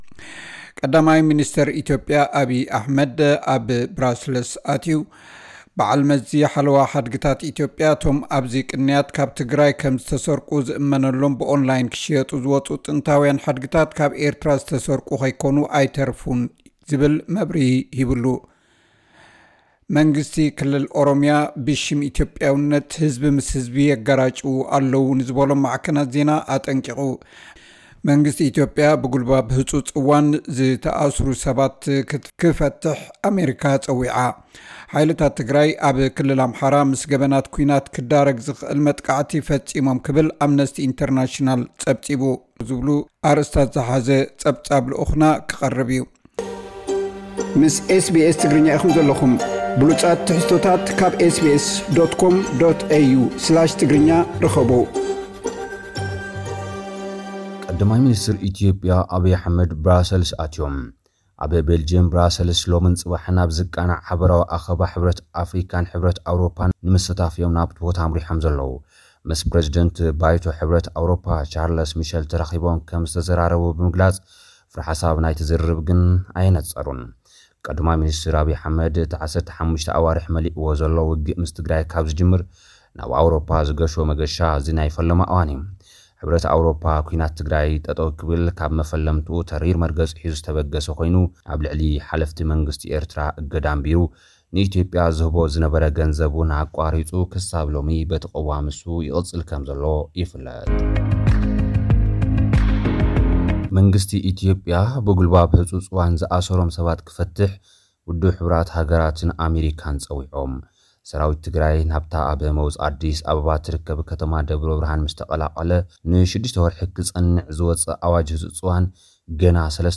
ዝብሉ ኣርእስታት ዝሓዘ ጸብጻብ ልኡኽና ክቐርብ እዩ።